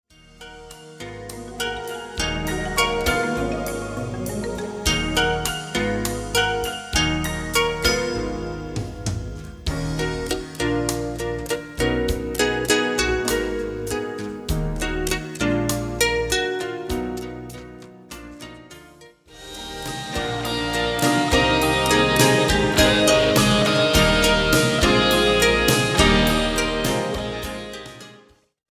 re-mastered, überarbeiteter Sound, Bonus Songs, Bonus Videos